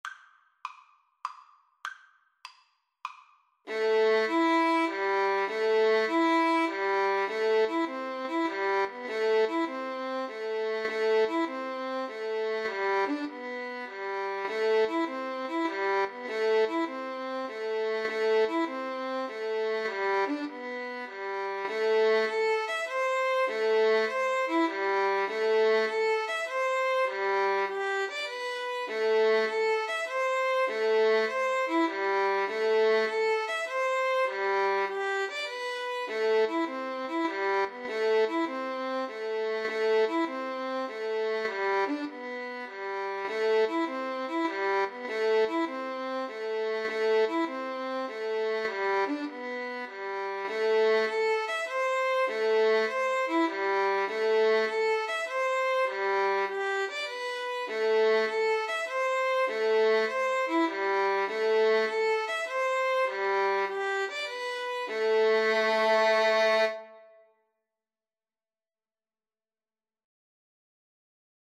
Play (or use space bar on your keyboard) Pause Music Playalong - Player 1 Accompaniment reset tempo print settings full screen
A minor (Sounding Pitch) (View more A minor Music for Violin Duet )
9/8 (View more 9/8 Music)
Traditional (View more Traditional Violin Duet Music)